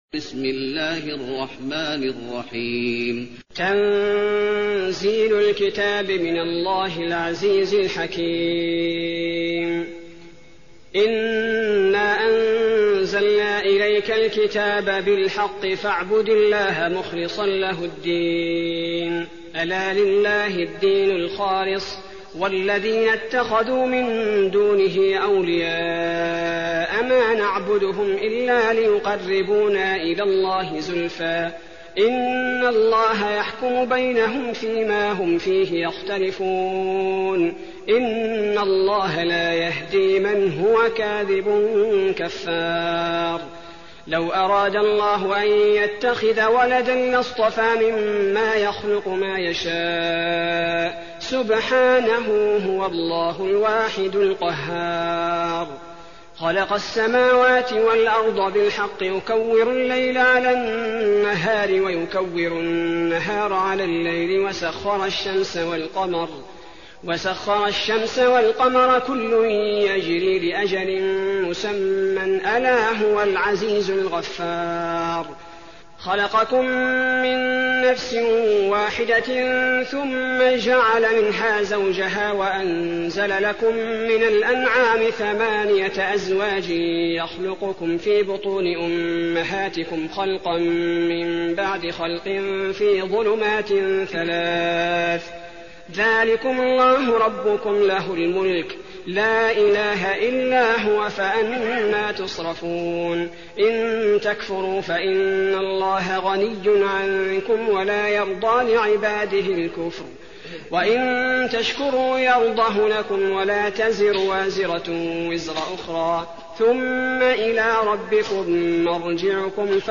المكان: المسجد النبوي الزمر